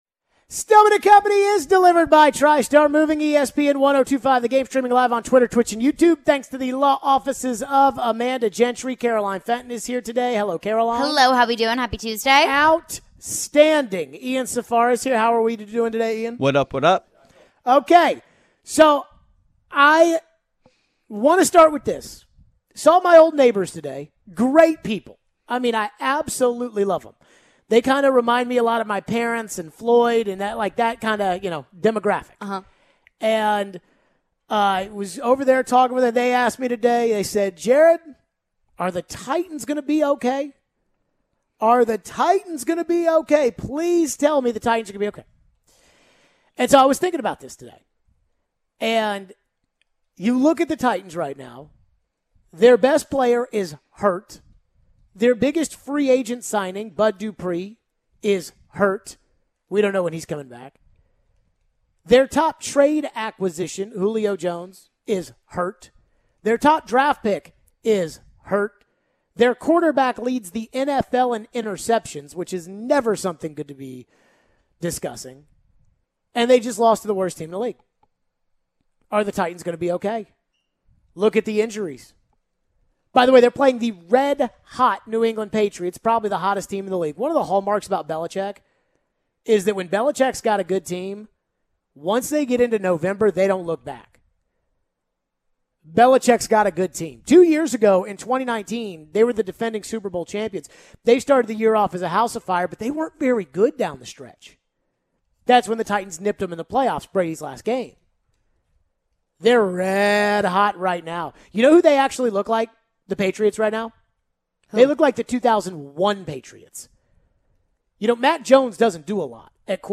Are Titans in Trouble? Tannehill troubles, phone and text lines and Predators huge win against Ducks